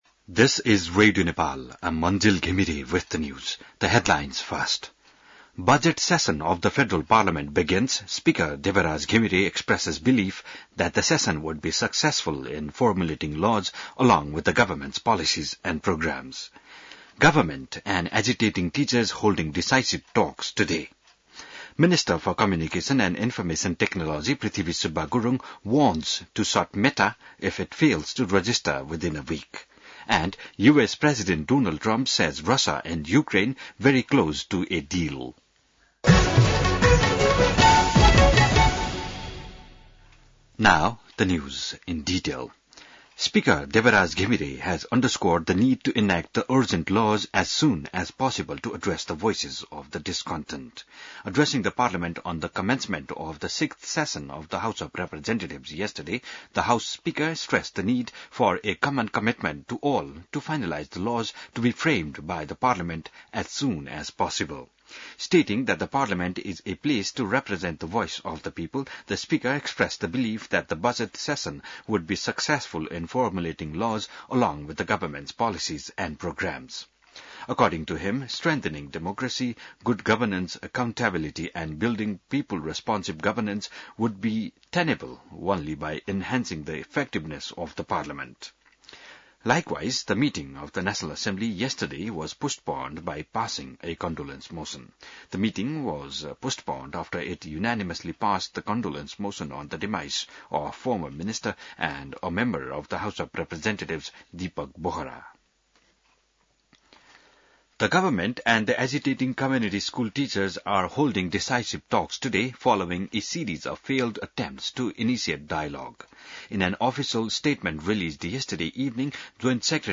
बिहान ८ बजेको अङ्ग्रेजी समाचार : १३ वैशाख , २०८२